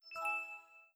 MinderiaOS Beta Shutdown.wav